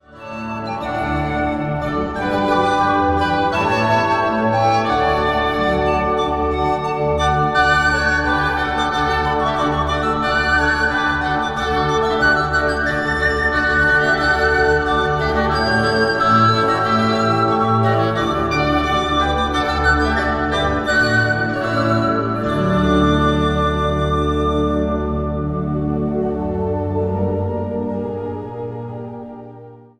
Kerstmuziek uitgevoerd door diverse koren en artiesten.
Zang | Gemengd koor
Zang | Mannenkoor